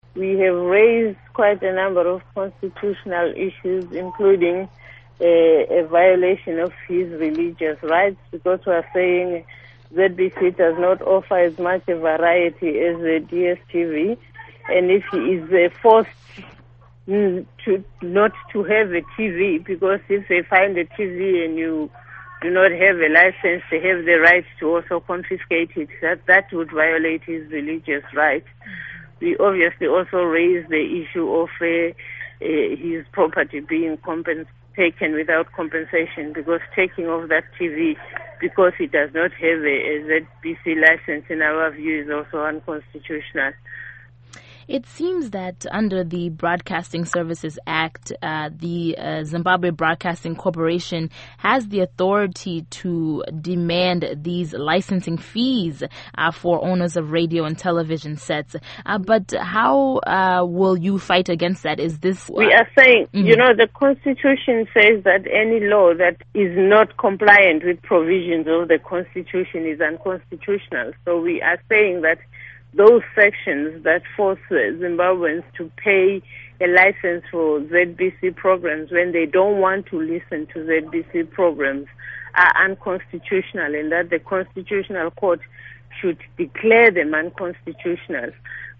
Interview With Beatrice Mtetwa